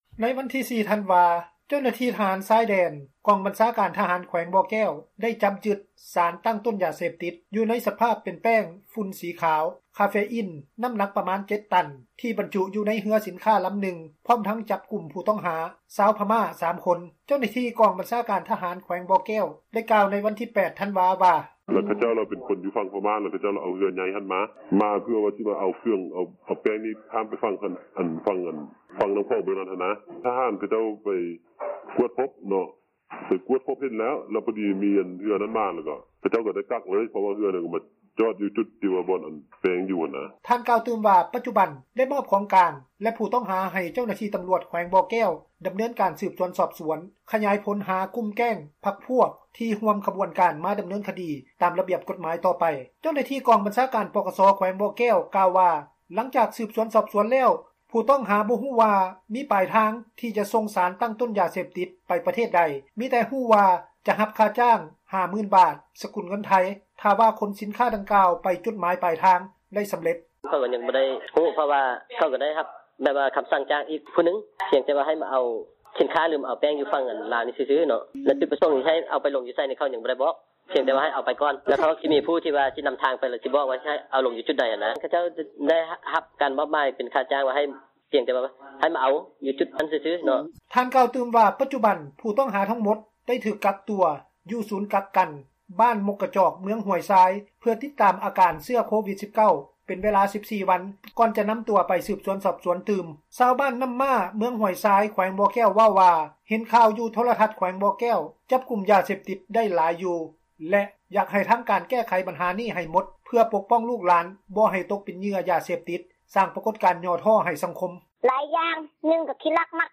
ນັກຂ່າວພົລເມືອງ